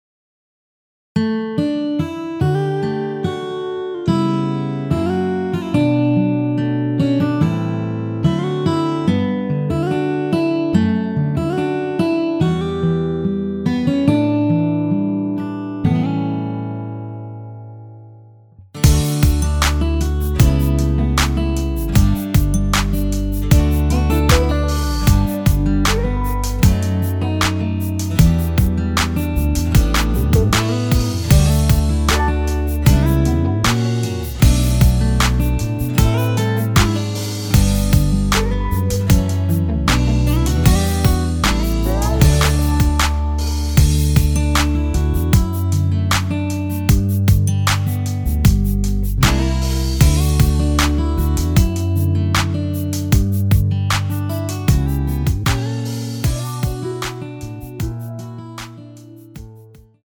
원키에서(-1)내린 MR입니다.
D
앞부분30초, 뒷부분30초씩 편집해서 올려 드리고 있습니다.
중간에 음이 끈어지고 다시 나오는 이유는